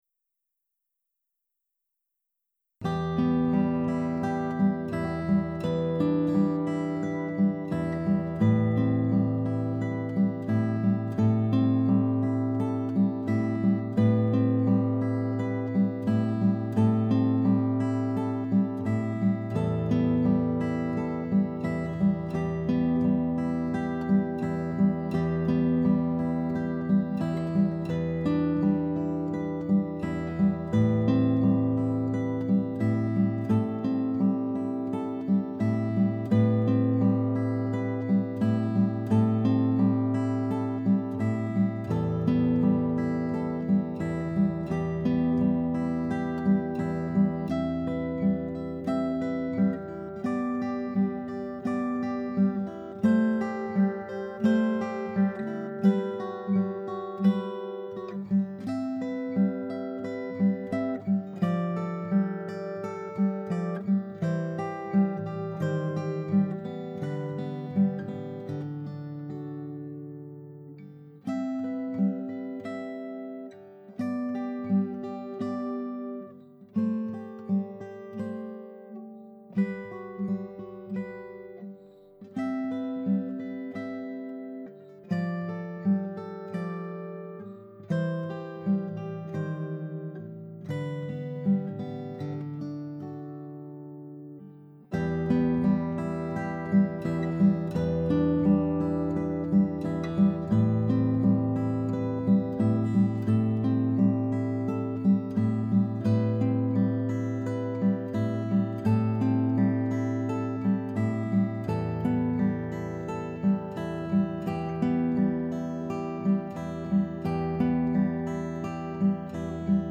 Guitar & Piano